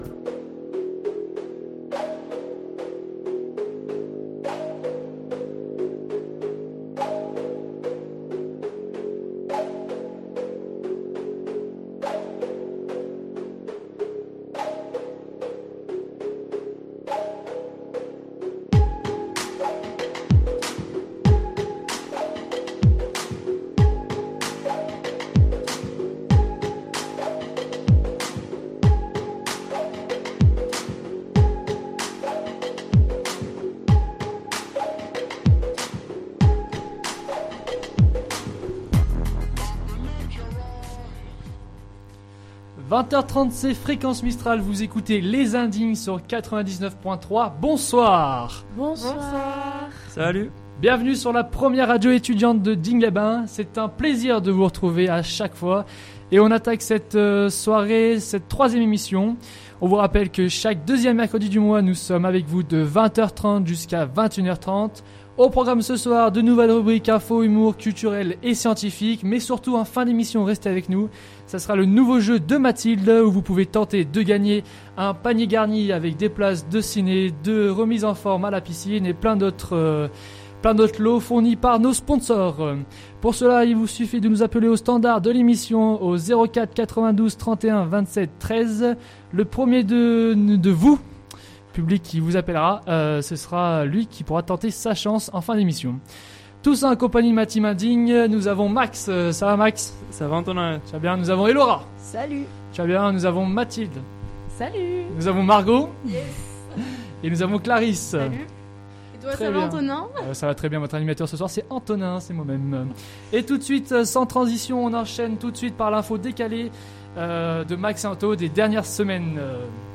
3ème émission en direct sur Fréquence Mistral Digne 99.3 avec les étudiants en Génie Biologique 1ère année